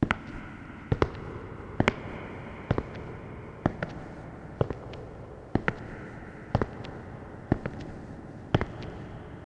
Звуки шагов, бега
Шаги в пустом зале с эхом